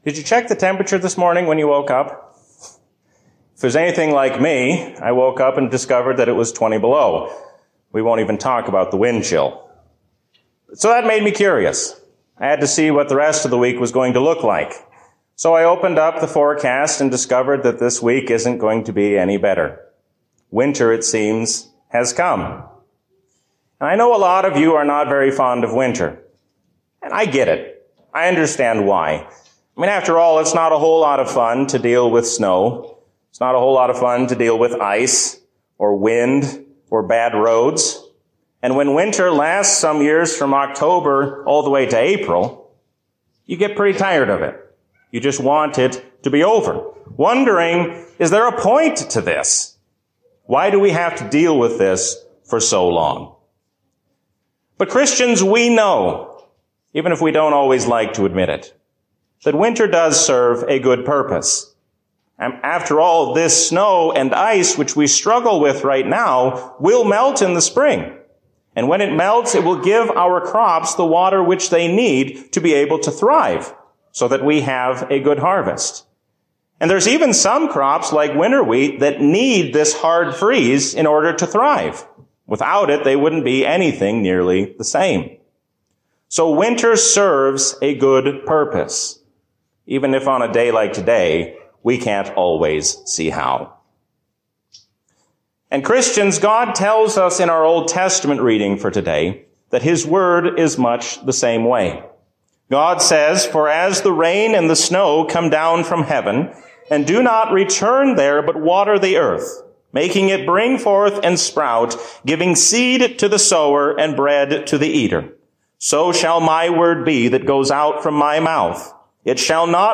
A sermon from the season "Trinity 2022." God is your loving Father and gives you the strength to face all of life's troubles.